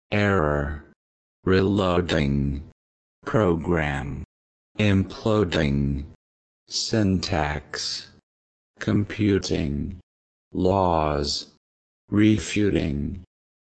Robot Voice